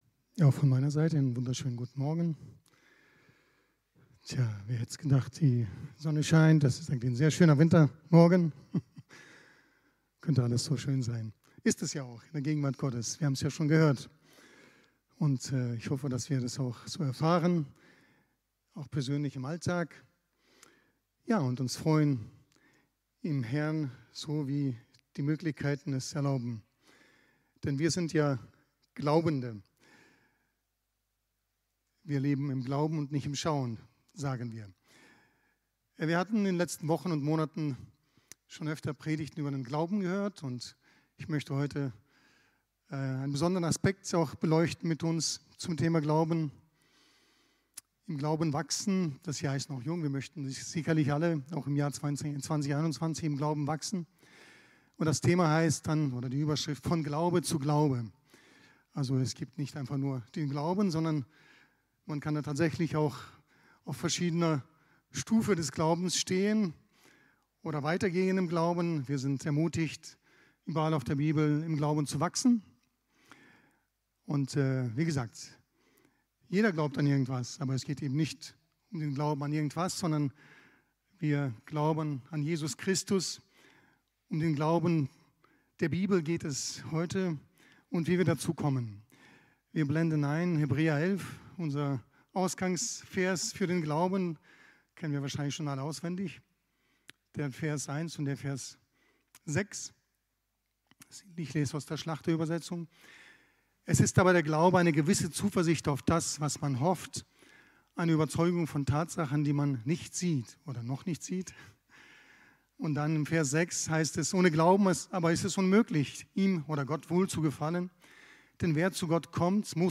Predigt
im Christlichen Zentrum Villingen-Schwenningen